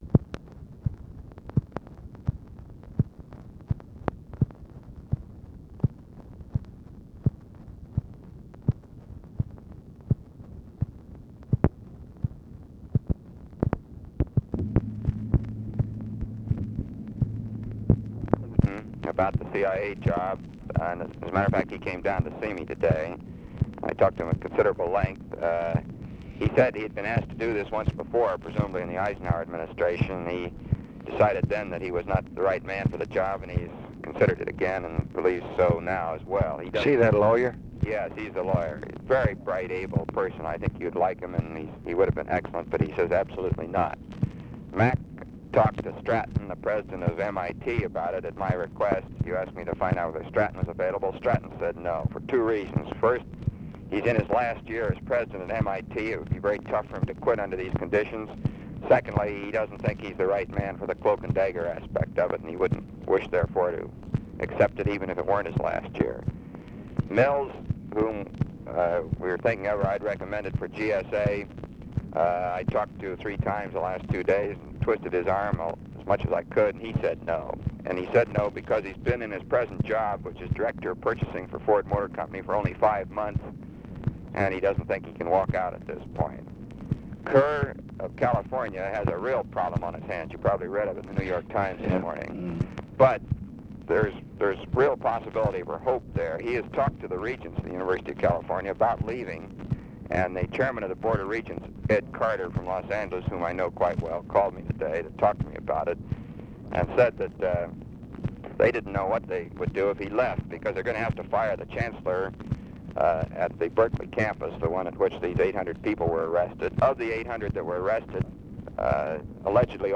Conversation with ROBERT MCNAMARA, December 4, 1964
Secret White House Tapes